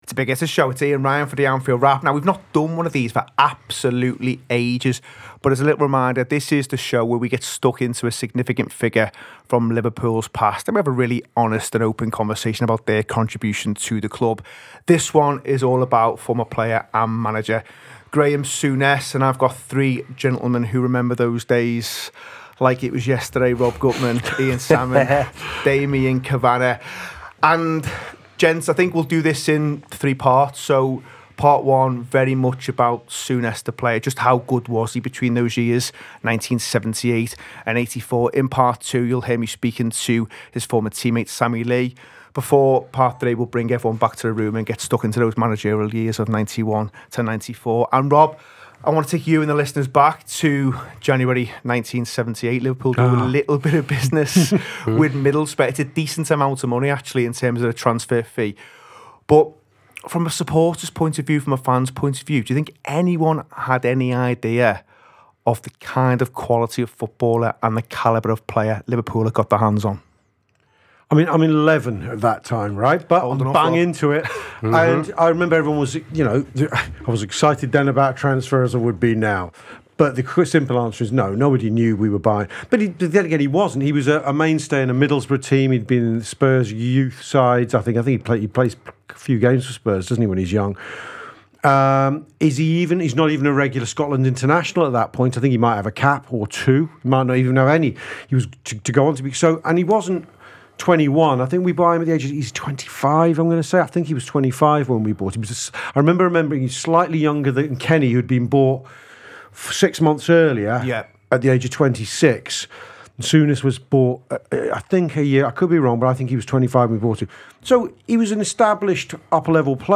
Looking back at the Liverpool career of Graeme Souness – his arrival, his rise to the top and his legacy – alongside special guest Sammy Lee.